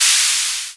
Closed Hats
Wu-RZA-Hat 49.wav